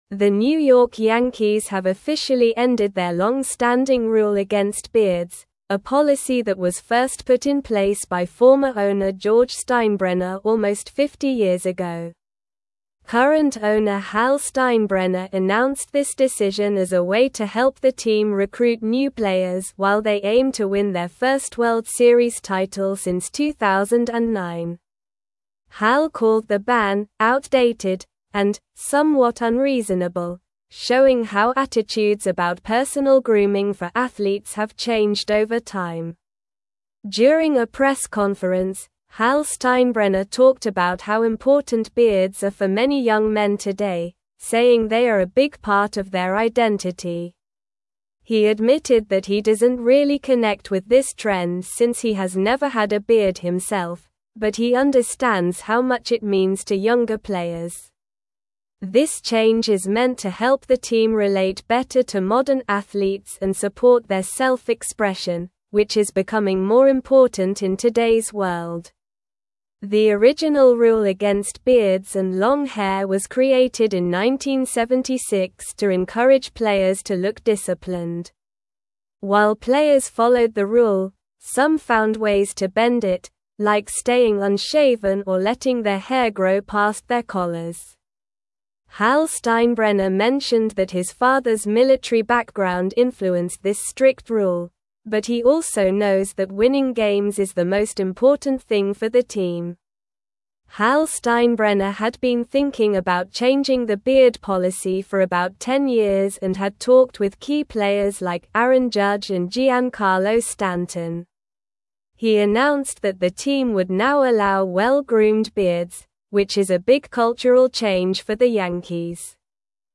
Slow
English-Newsroom-Upper-Intermediate-SLOW-Reading-Yankees-Lift-Longstanding-Beard-Ban-for-Players.mp3